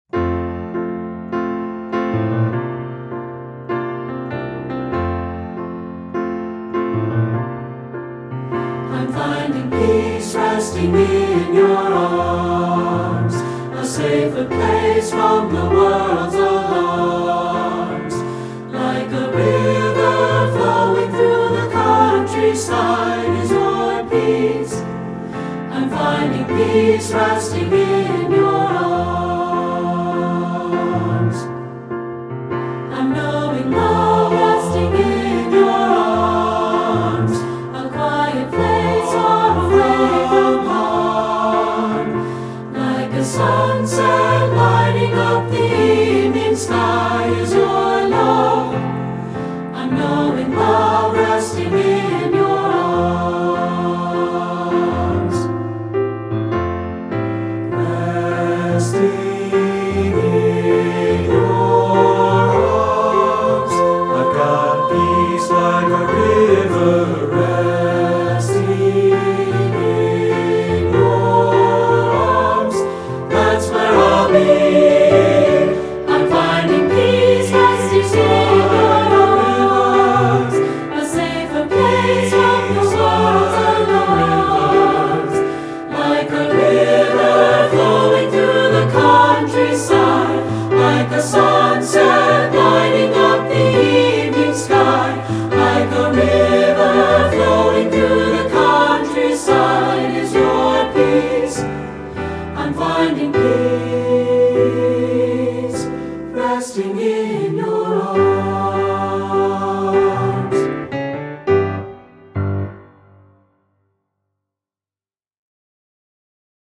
Voicing: S(A)B and Piano